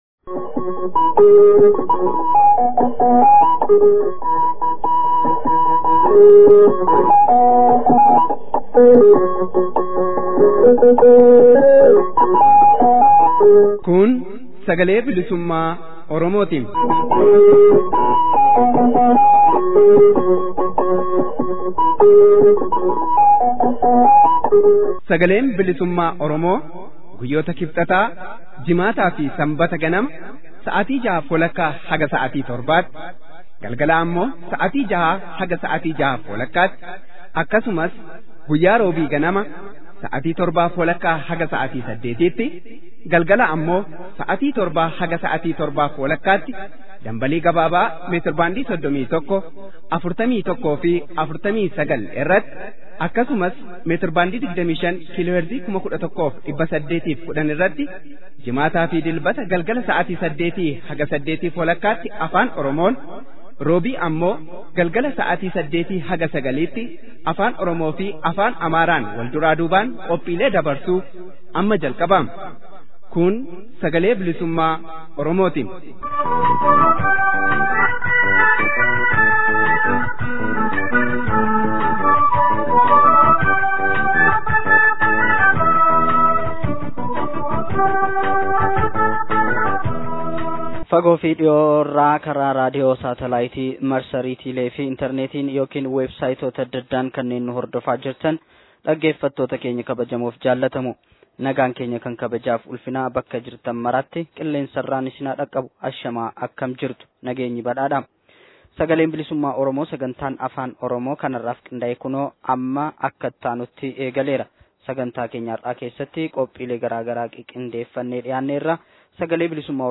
SBO Gurraandhala 19,2017. Oduu
gaaffii fi deebii